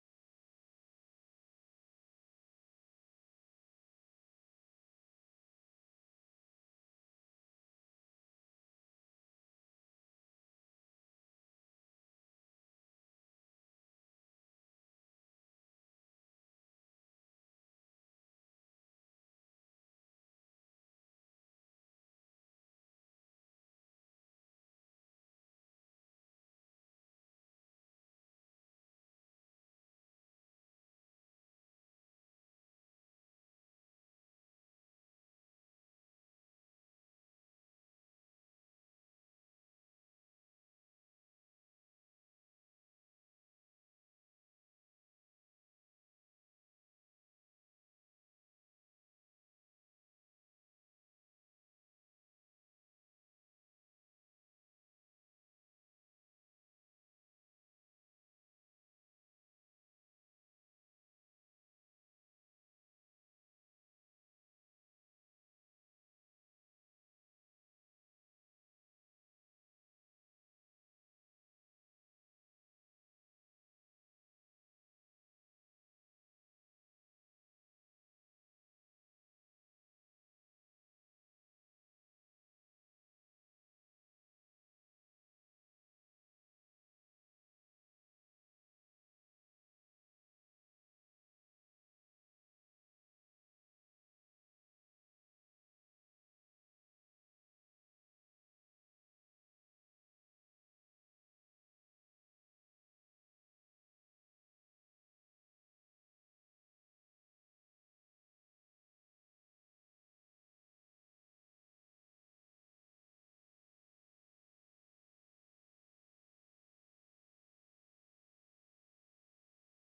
La vraie période des Slihot (avec le son ) 00:07:10 La vraie période des Slihot (avec le son ) שיעור מ 06 אוגוסט 2023 07MIN הורדה בקובץ אודיו MP3 (6.55 Mo) הורדה בקובץ וידאו MP4 (11 Mo) TAGS : שיעורים קצרים